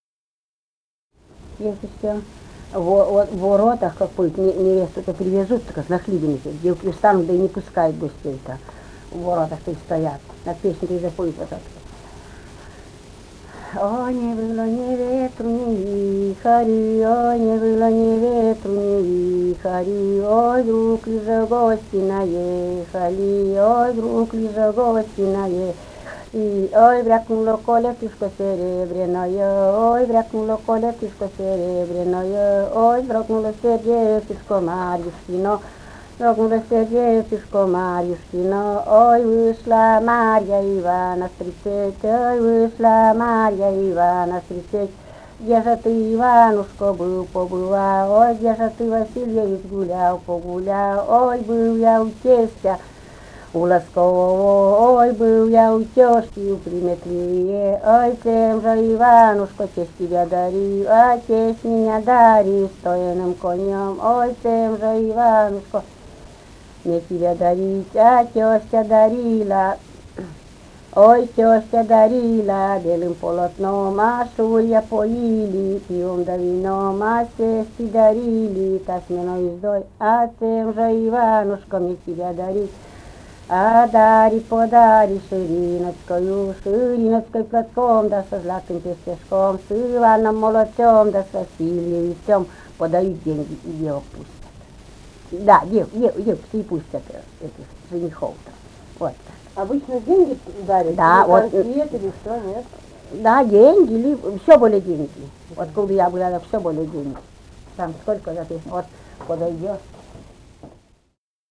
Этномузыкологические исследования и полевые материалы
Вологодская область, д. Малая Тигинского с/с Вожегодского района, 1969 г. И1129-09